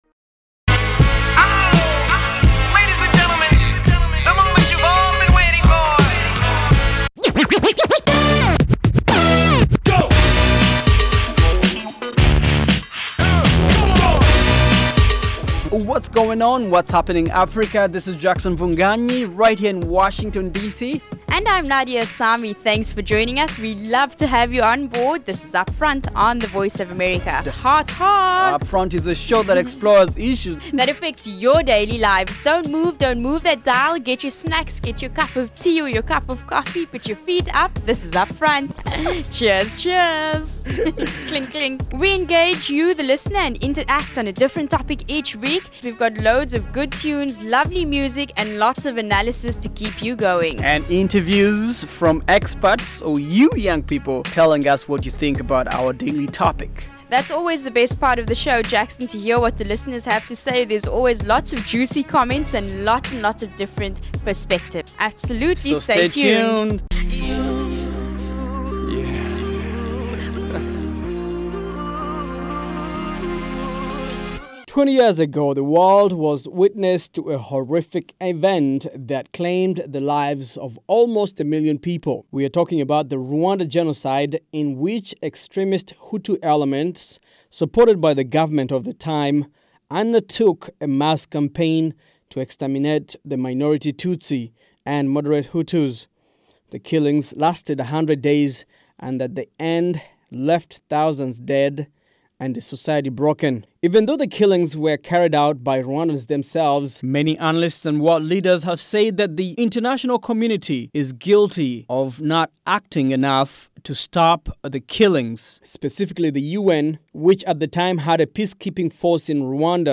On this fresh, fast-paced show